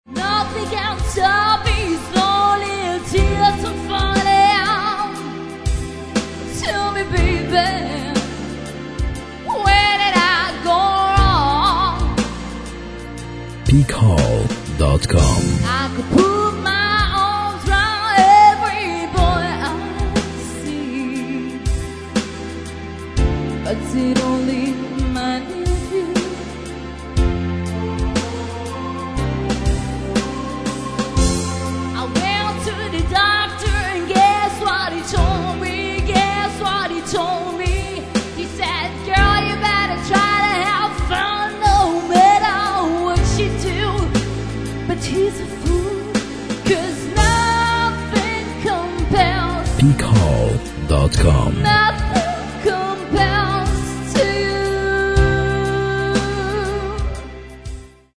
English Karaoke Talented Singers